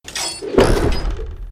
catapult_launch.ogg